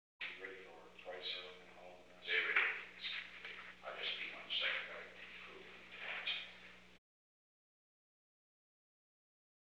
Conversation: 798-014
Recording Device: Oval Office
The Oval Office taping system captured this recording, which is known as Conversation 798-014 of the White House Tapes.
Location: Oval Office